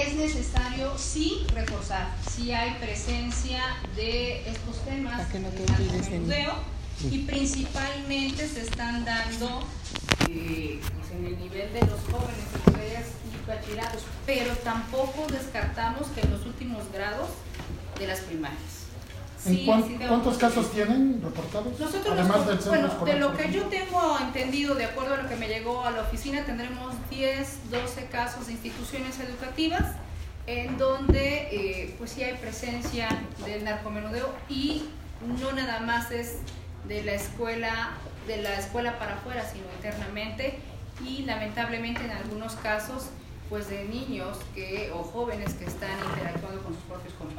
En conferencia de prensa, en sala de regidores, Aguirre Junco consideró que la presencia de los padres de familia es fundamental para su desarrollo y avance en las aulas, por ello se busca trabajará también de manera coordinada con las autoridades municipales, y estales a través de la Secretaría de Educación Pública (SEP).